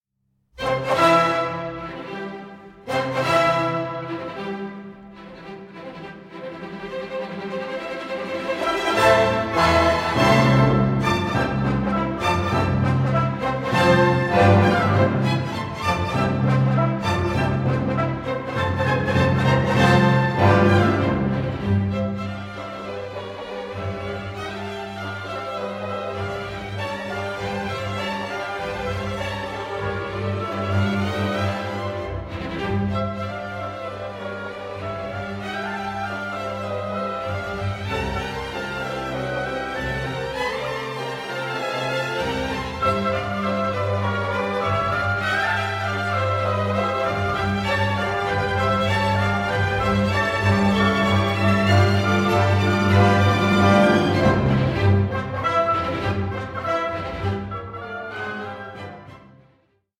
Scherzo. Allegro vivace – Trio 13:59
PLAYED WITH VITALITY AND INTENSITY